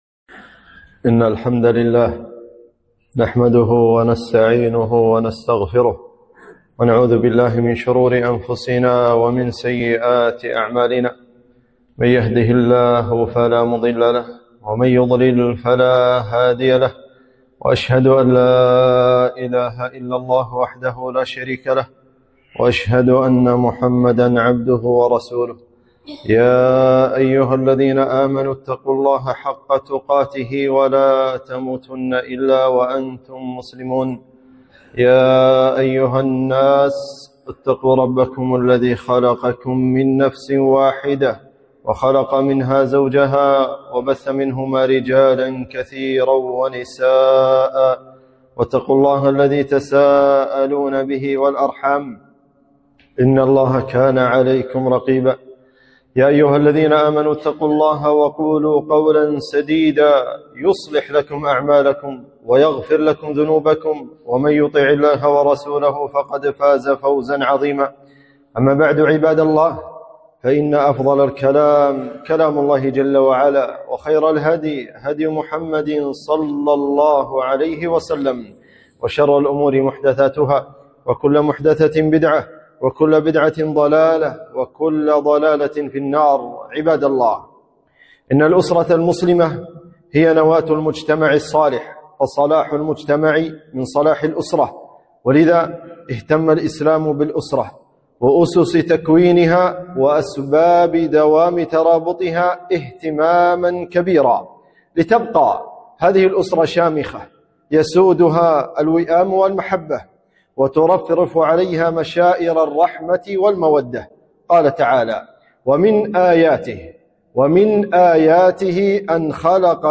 خطبة - مسؤولية الزوجان في تربية الأبناء